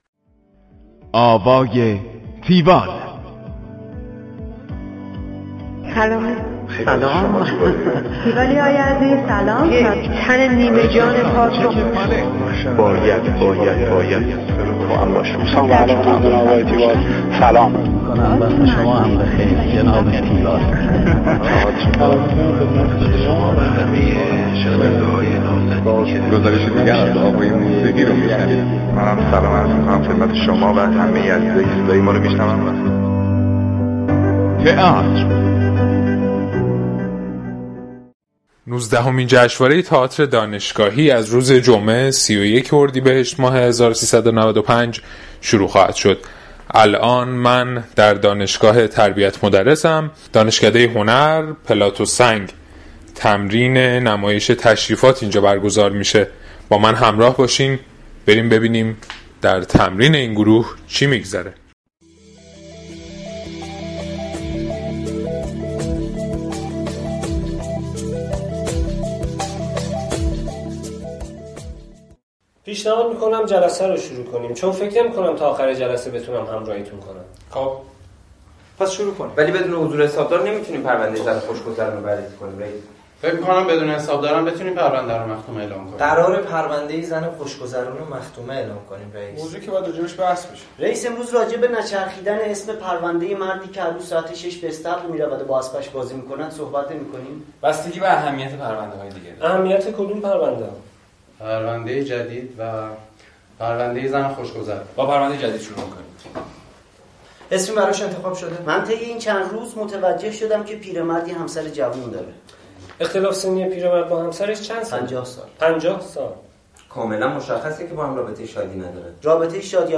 گزارش آوای تیوال از نمایش تشریفات